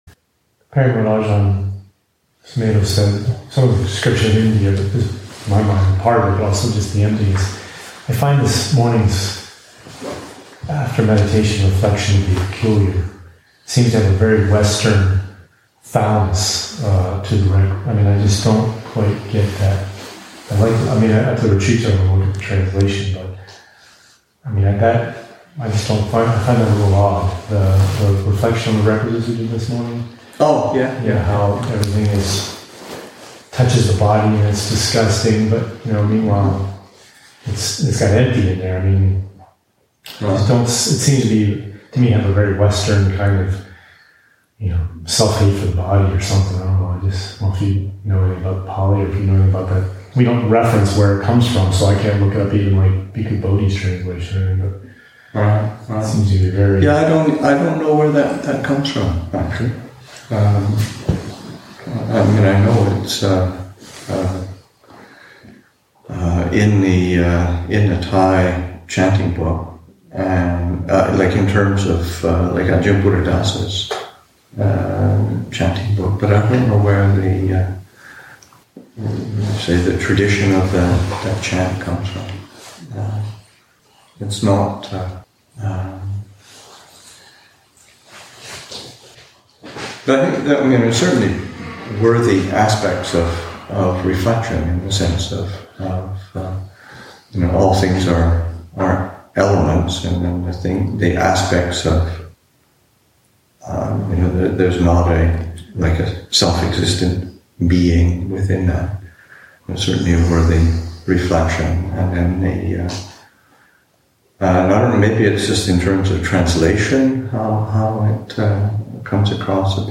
1. Discussion about the translation, meaning, and interpretation of the “Reflection on the Off-Putting Qualities of the Requisites” chant.